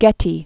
(gĕtē), J(ean) Paul 1892-1976.